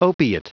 Prononciation du mot opiate en anglais (fichier audio)
Prononciation du mot : opiate